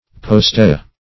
Postea \Post"e*a\, n. [L., after these or those (things),